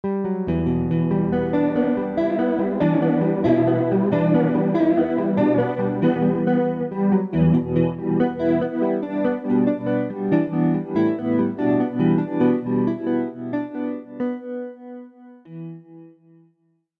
Add luscious warmth and body to your tracks with up to 10 voices of chorusing.
Each sequence is in a dry version and then effected in different ways.
Master Chorus.mp3